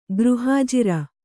♪ gřhājira